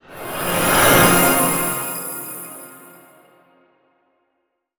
light_in_dark_spell_04.wav